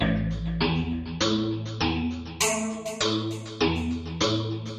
标签： 100 bpm Electronic Loops Bass Synth Loops 827.05 KB wav Key : A
声道立体声